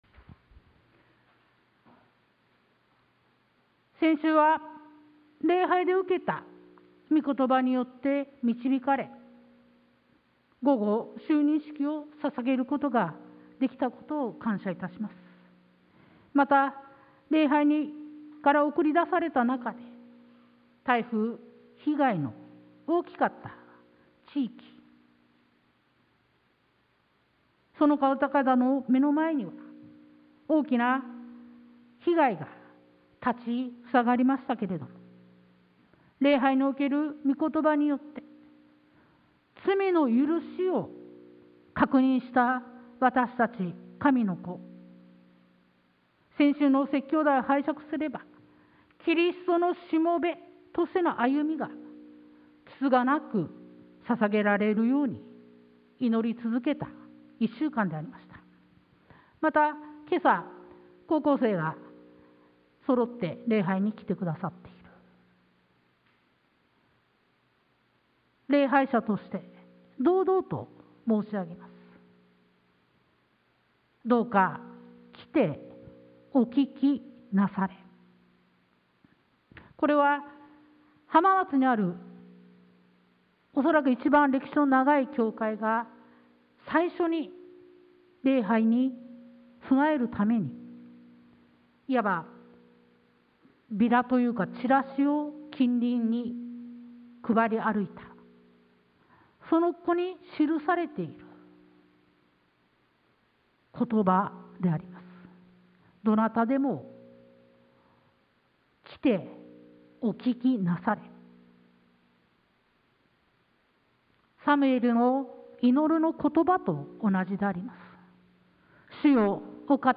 sermon-2022-10-02